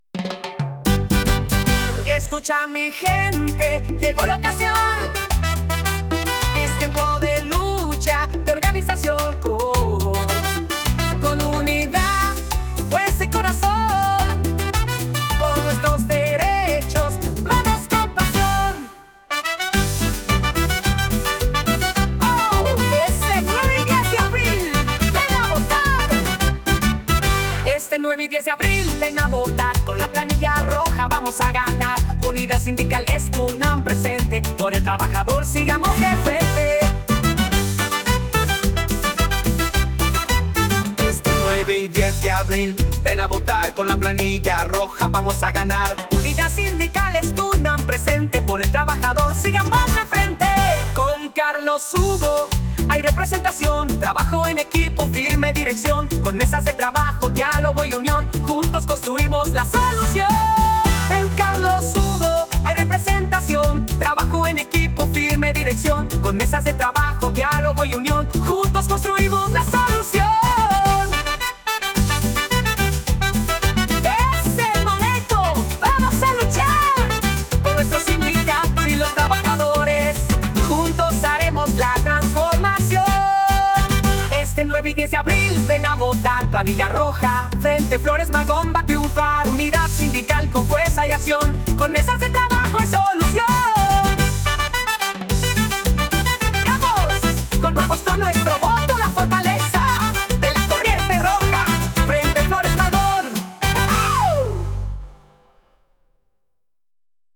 Género: Norteño